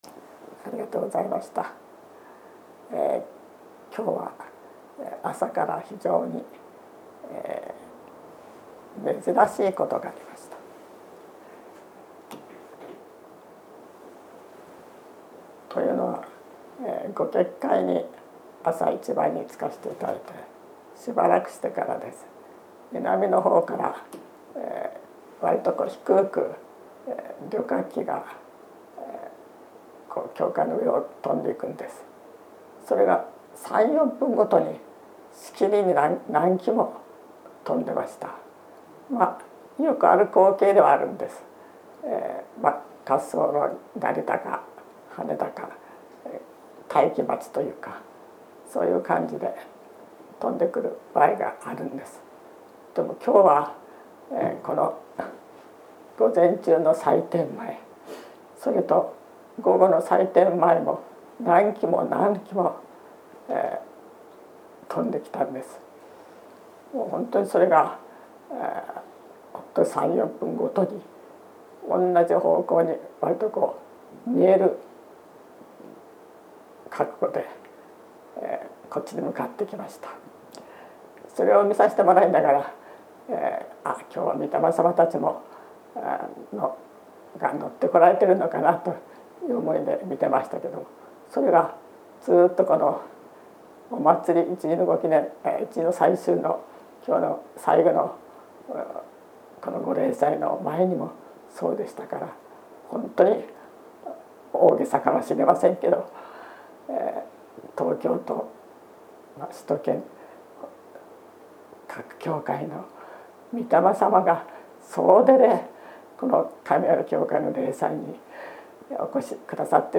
秋季霊祭教話